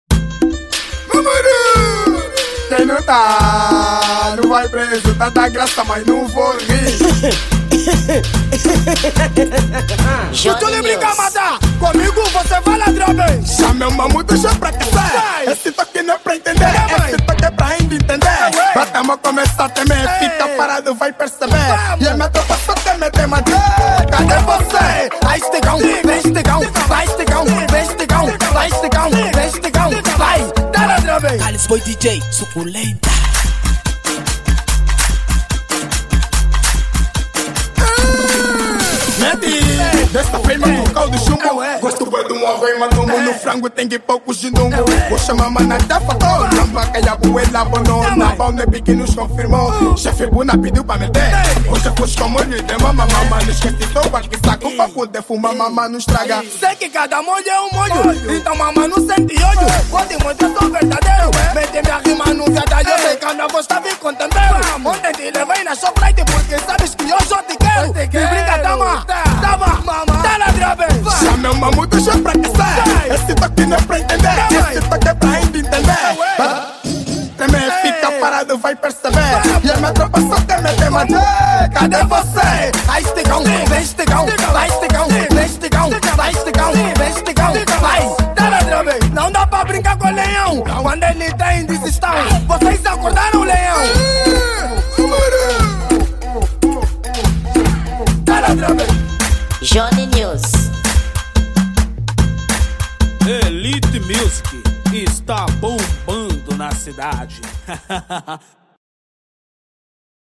Kuduro
Gênero: Amapiano